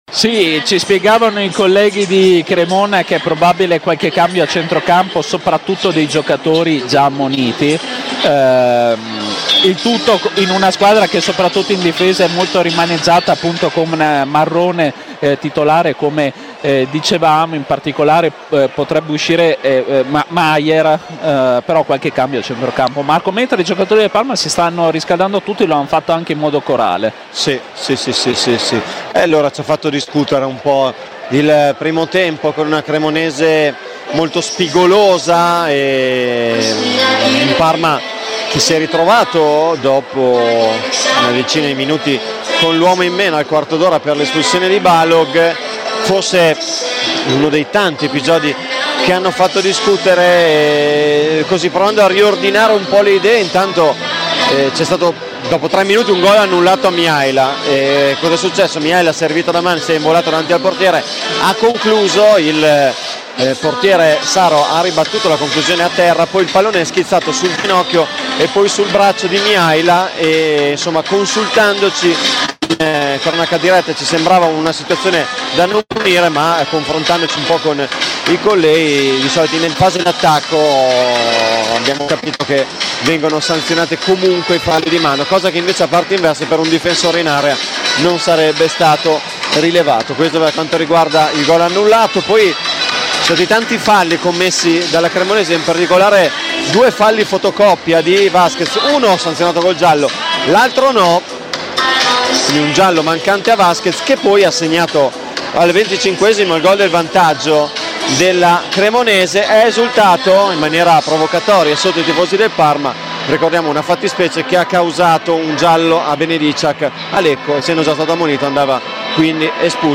Radiocronaca
Commento tecnico